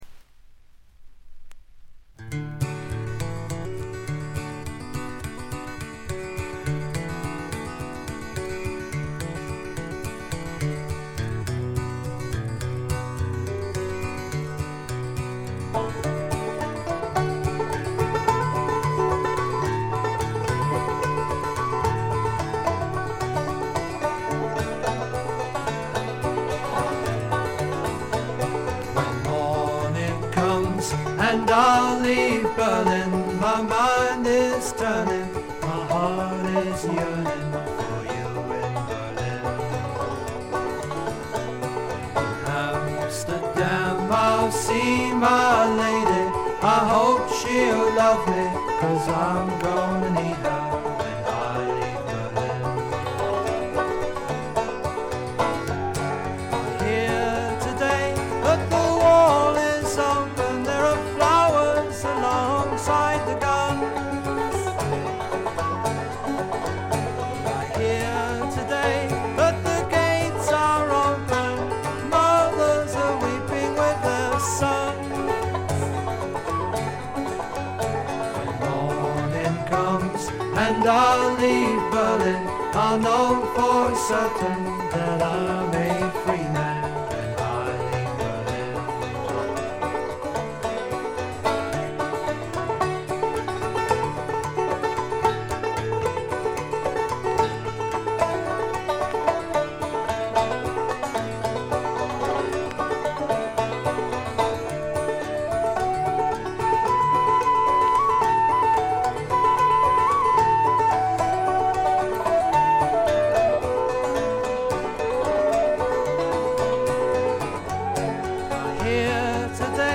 ところどころでチリプチ（特にB2は目立ちます）。鑑賞を妨げるようなノイズはありません。
試聴曲は現品からの取り込み音源です。